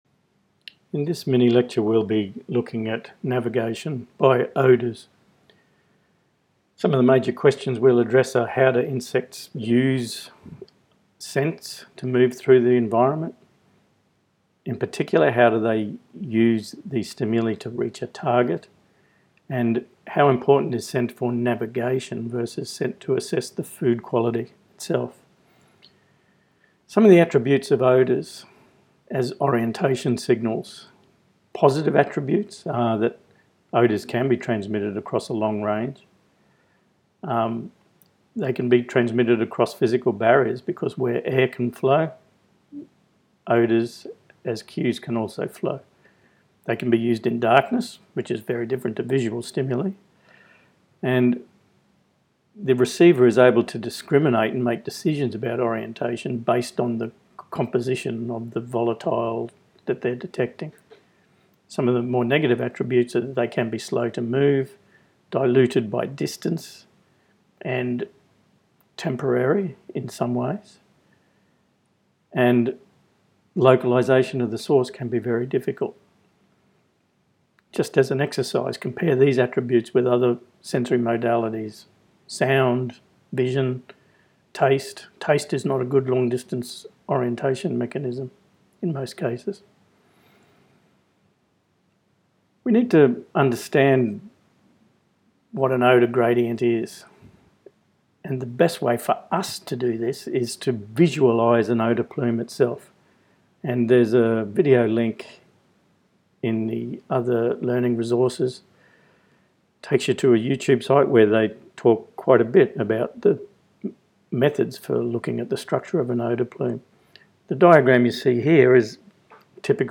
Mini-lecture: